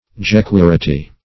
Search Result for " jequirity" : The Collaborative International Dictionary of English v.0.48: Jequirity \Je*quir"i*ty\, n., or Jequirity bean \Je*quir"i*ty bean`\ [Prob. fr. a native name.]
jequirity.mp3